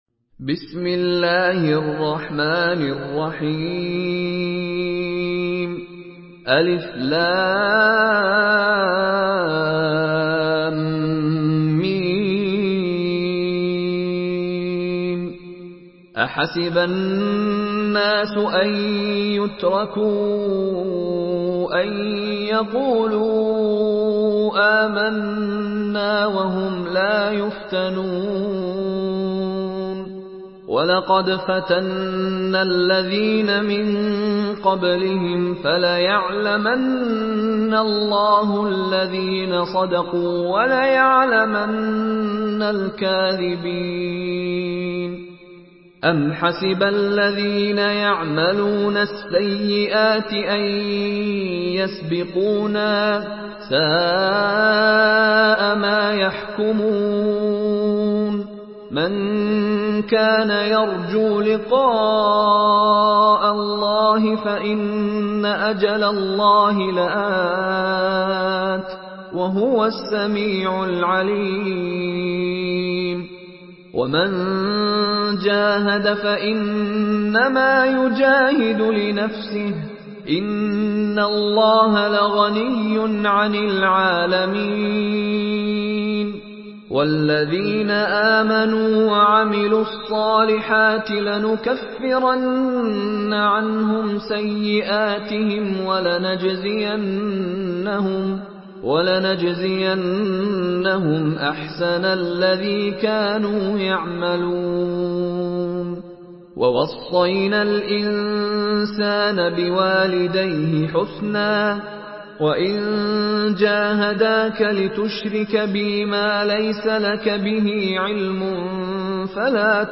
تحميل سورة العنكبوت بصوت مشاري راشد العفاسي
مرتل حفص عن عاصم